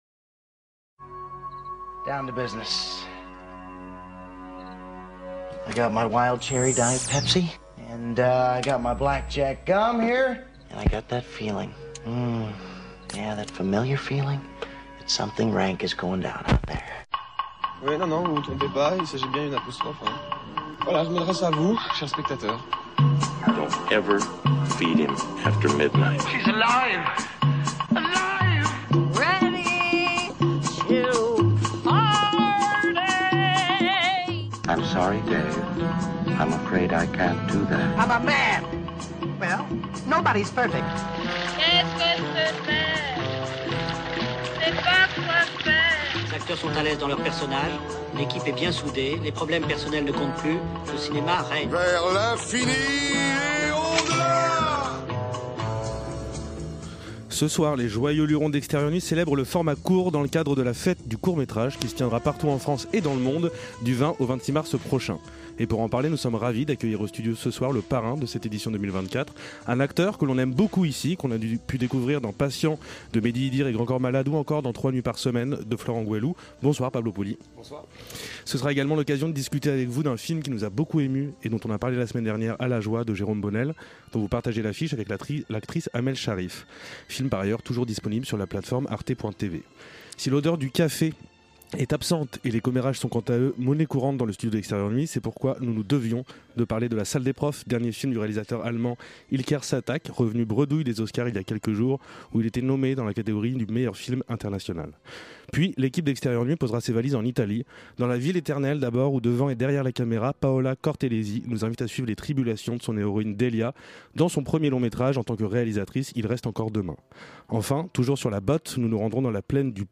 Ce soir les joyeux lurons d’Extérieur Nuit célèbrent le format court dans le cadre de la FÊTE DU COURT MÉTRAGE qui se tiendra partout en France et dans le monde du 20 au 26 mars prochain. Pour en parler, nous sommes ravis d’accueillir au studio ce soir le parrain de cette édition 2024, un acteur que l'on aime beaucoup ici que l'on a pu découvrir dans PATIENTS de Mehdi Idir et Grand Corps Malade ou encore dans TROIS NUITS PAR SEMAINE de Florent Gouëlou : Pablo Pauly.
Si l’odeur du café est absente, les commérages sont quant à eux monnaie courante dans le studio d’extérieur nuit, c’est pourquoi nous nous devions de parler de LA SALLE DES PROFS, dernier film du réalisateur allemand İlker Çatak revenu bredouille des Oscars il y a quelques jours.